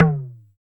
LOGTOM HI M.wav